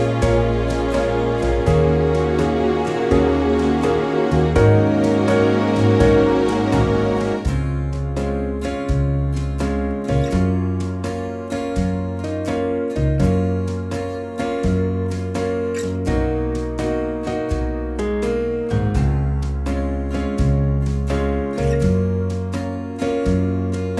no Backing Vocals Easy Listening 3:33 Buy £1.50